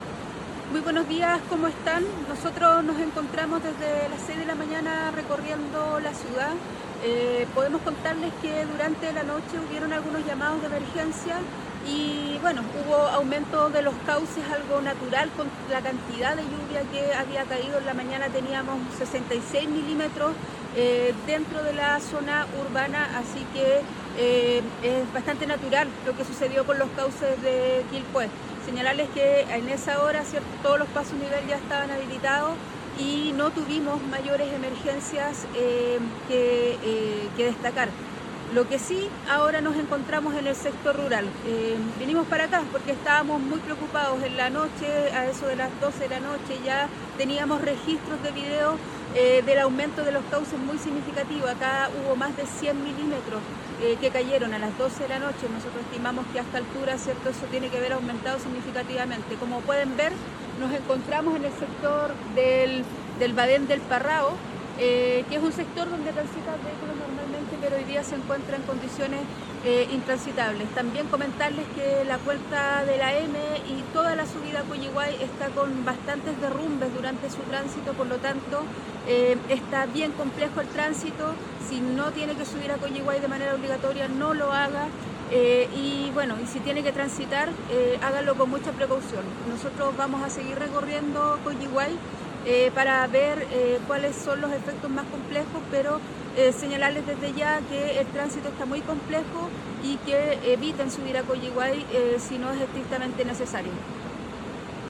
alcaldesa-Valeria-Melipillan-visitas-a-sectores-rurales-por-lluvia.mp3